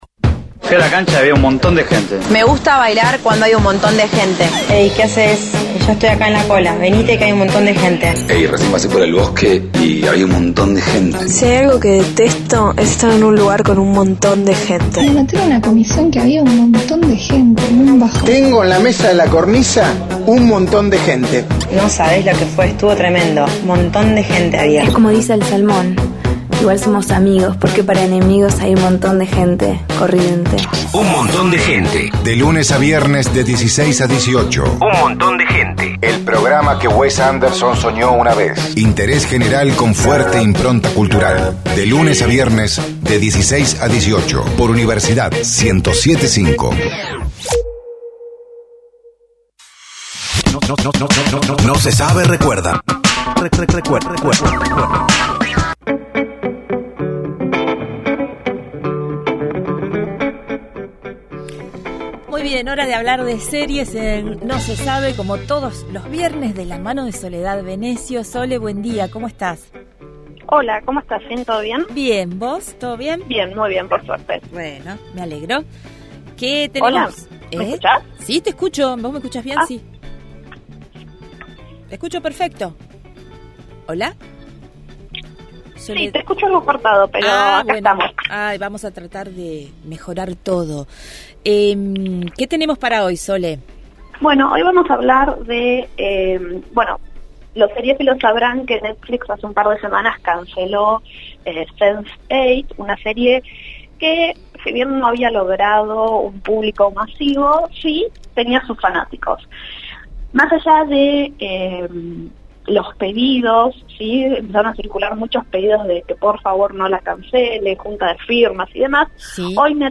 Columna: Netflix y las cancelaciones – Radio Universidad